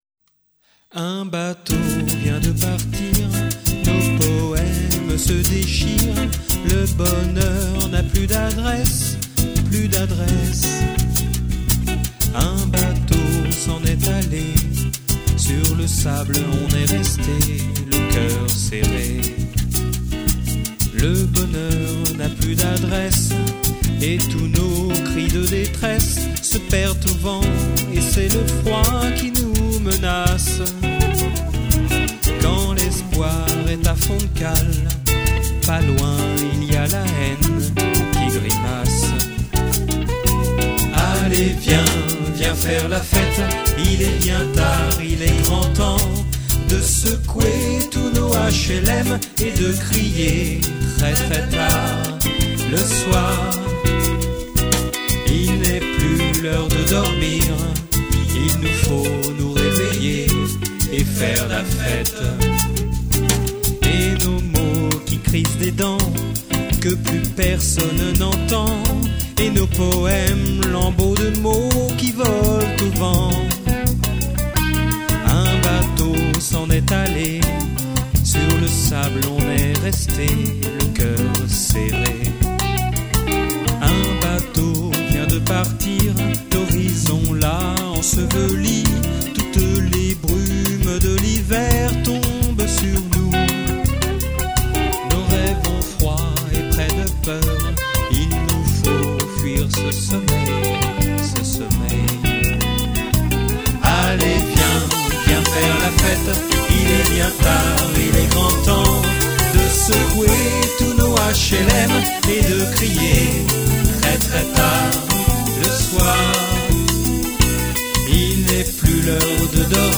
Télécharger la maquette MP3